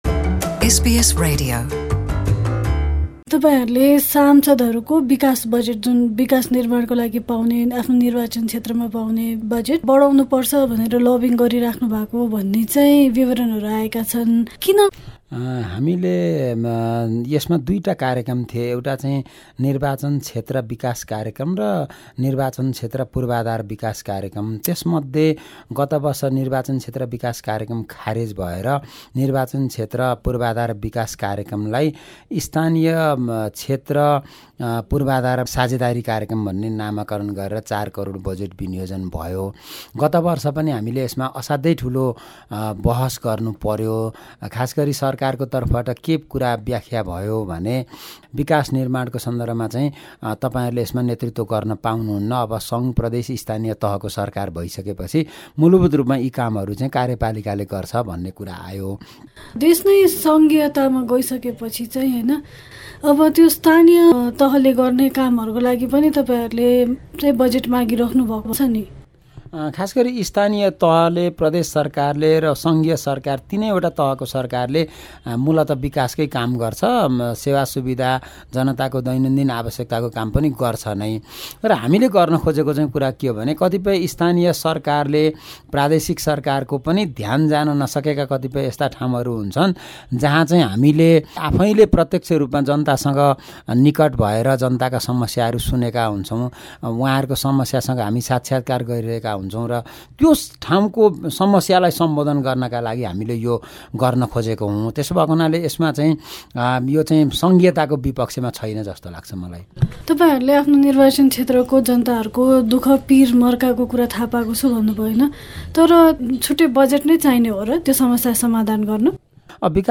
नेपालमा निर्वाचन क्षेत्र विकासका लागि सांसदहरुलाई प्रदान गरिने रकममा वृद्धि गर्निपर्छ भनेर विभिन्न संचार माध्यममा खुलेरै बोलिरहेका सत्तारुढ दल नेकपाका एकजना सासंद विशाल भट्टराईसँग हामीले गरेको कुराकानी।
Nepal Communist Party MP Bishal Bhattarai spoke to SBS Nepali.